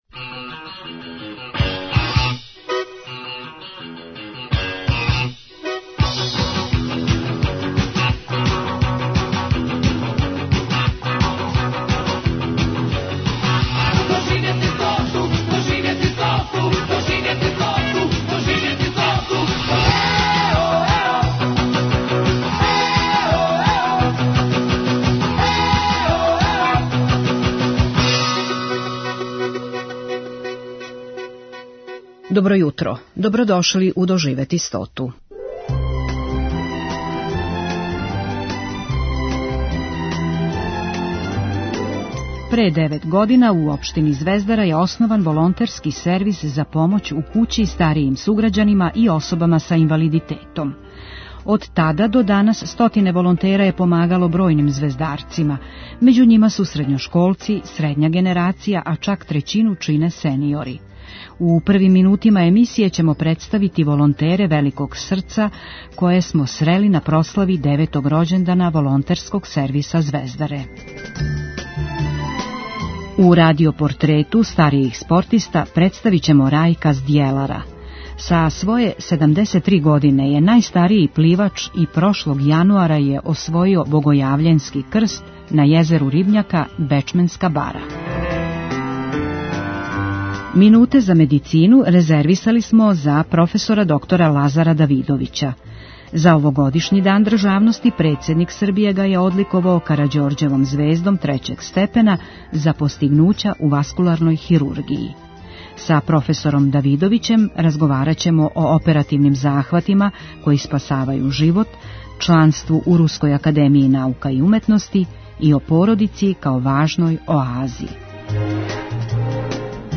У првим минутима емисије ћемо представити волонтере великог срца које смо срели на прослави деветог рођендана Волонтерског сервиса Звездаре.